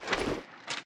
equip_gold2.ogg